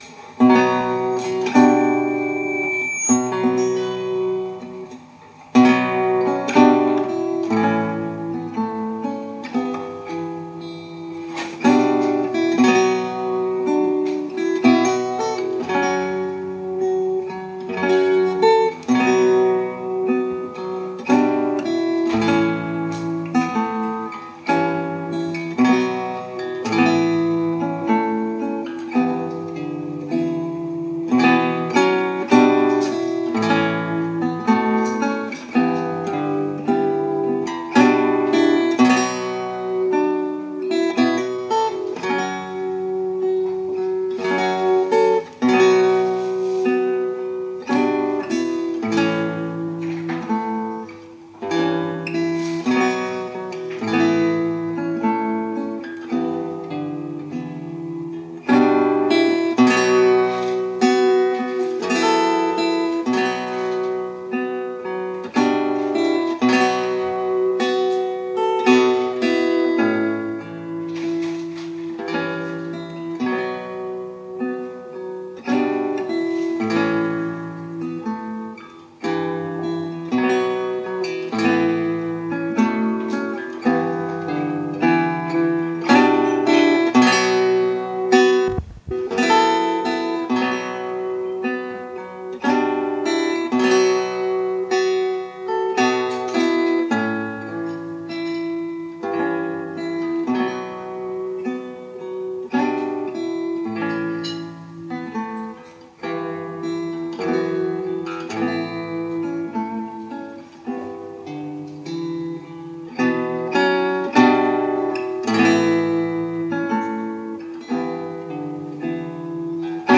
playing fiddle
Duncan Johnstone’s famous pipe march composed around 1974.